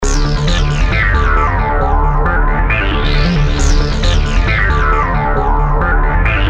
先使用第一狠招使用Matrix控制loop单独切片的音高，再增加一个滤波效果，效果请听
80_manip_matrix-pitched.mp3